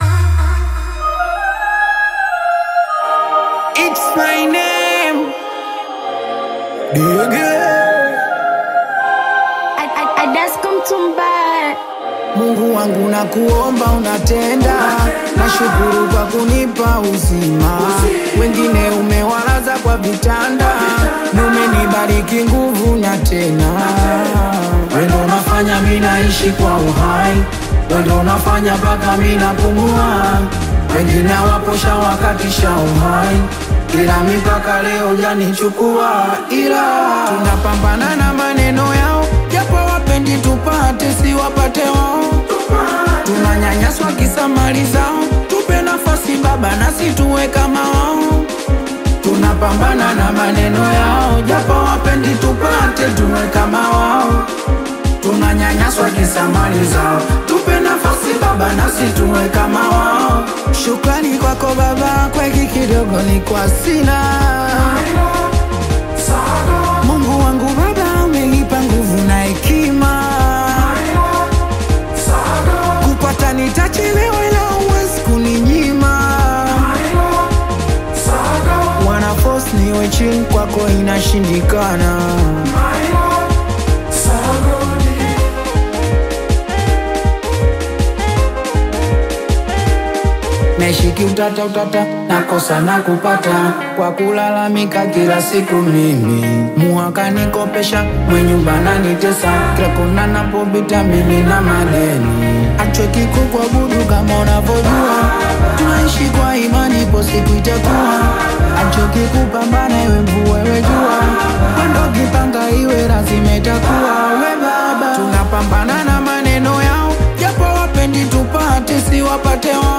infectious rhythm
smooth, dynamic vocals